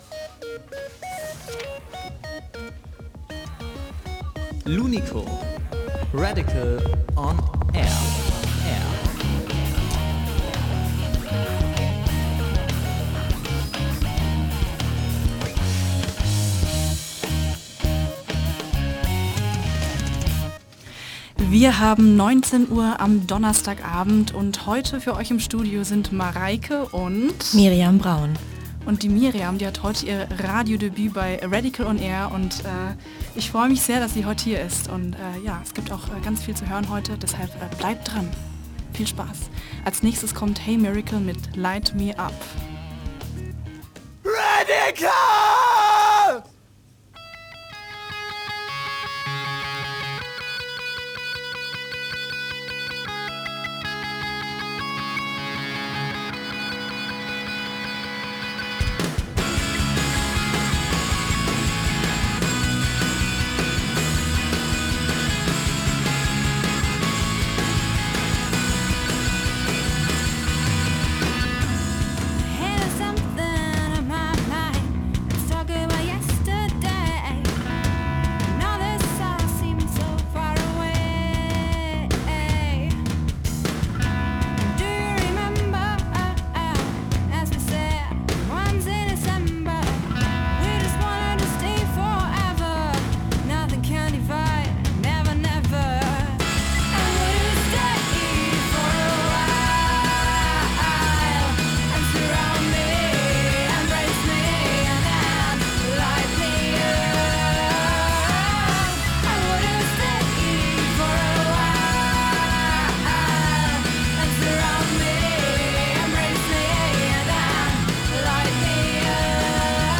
live im Interview THE IGNITION